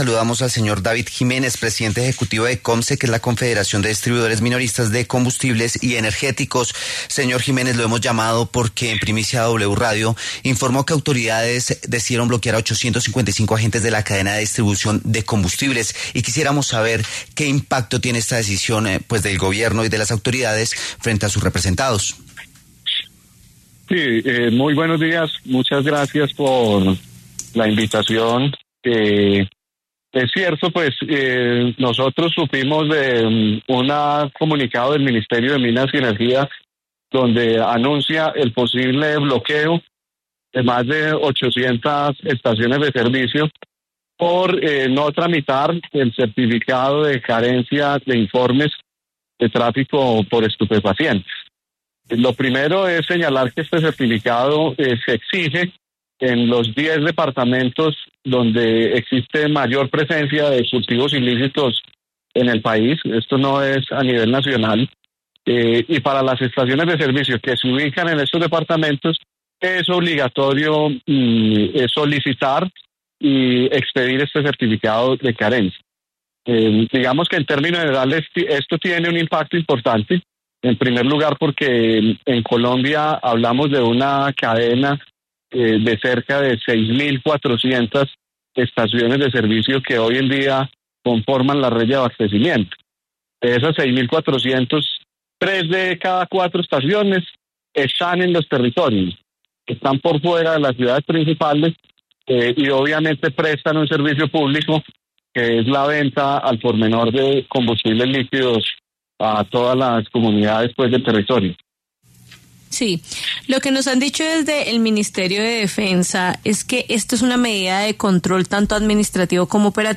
La W Radio